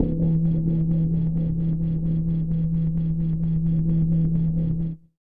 50 RESONAT-L.wav